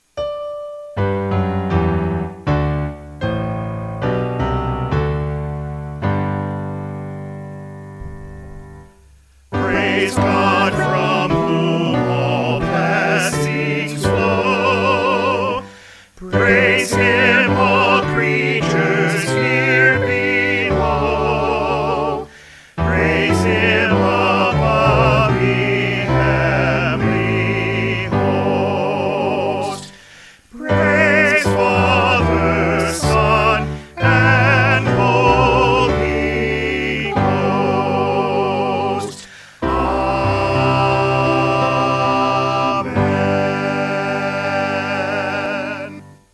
4 Part - MP3 (Listen)